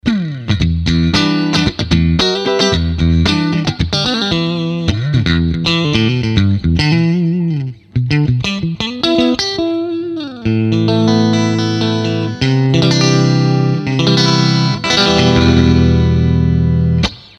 Toma 1: Sonido Limpio con stratocaster Fender Squier.
GTS90 clean Pos4.mp3